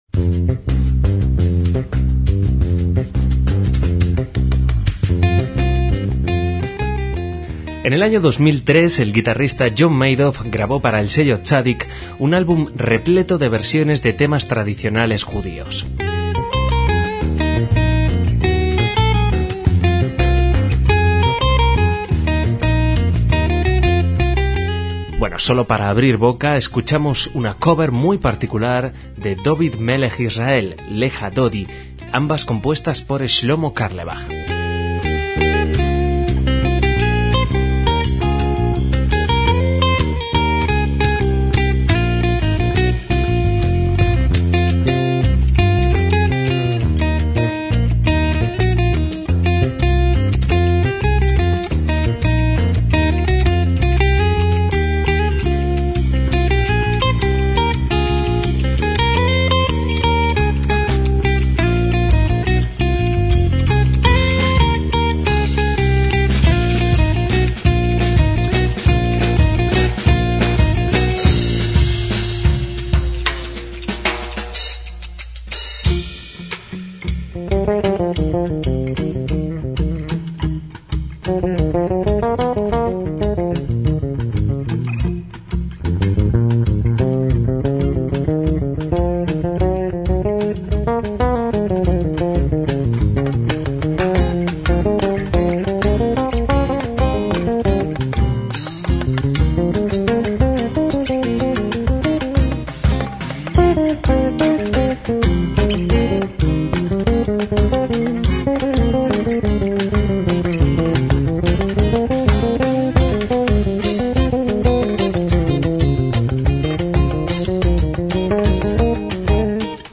funk afrobeat
con una formación de 13 músicos